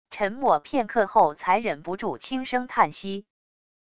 沉默片刻后才忍不住轻声叹息wav音频